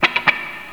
RIFFGTRLP2-L.wav